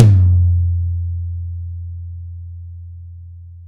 Index of /90_sSampleCDs/Best Service - Real Mega Drums VOL-1/Partition H/DRY KIT 2 GM